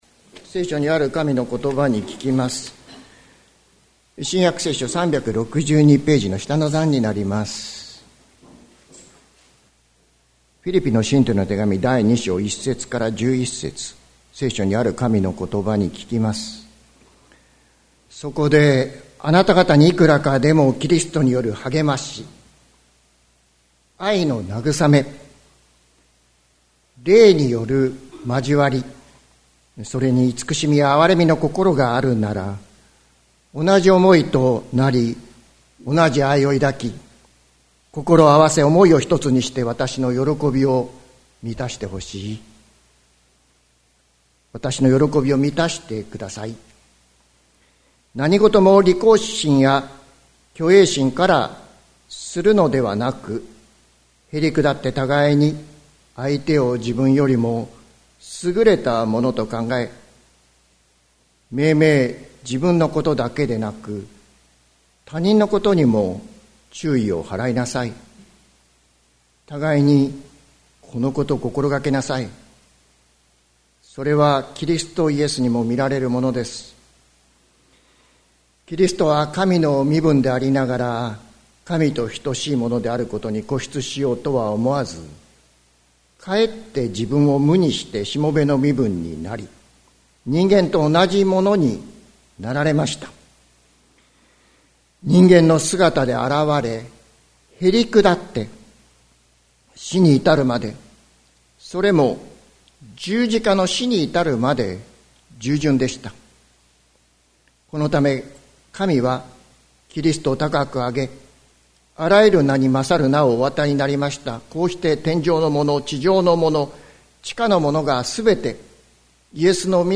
2024年05月26日朝の礼拝「キリスト賛歌を歌おう」関キリスト教会
説教アーカイブ。